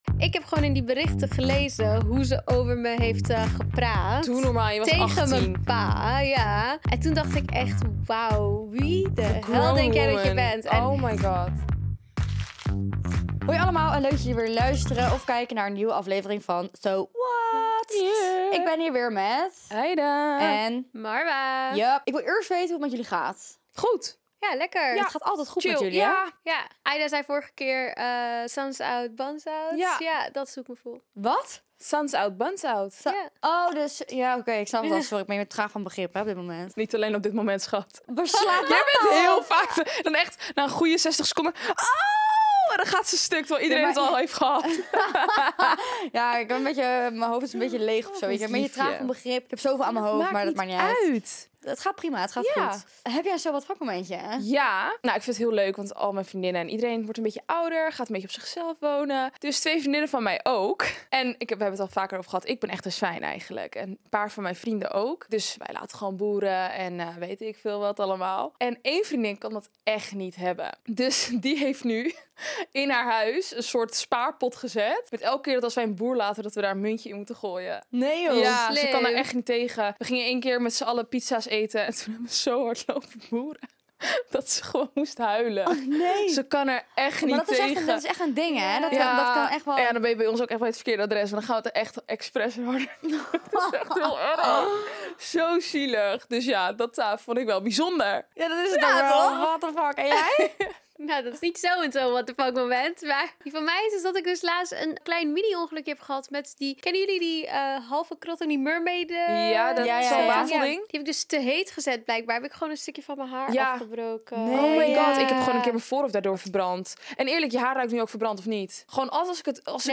Een aflevering vol openheid, (h)erkenning, diepe gesprekken en girl talk met een rauw randje. Zoals altijd: eerlijk, intens en soms een beetje chaotisch.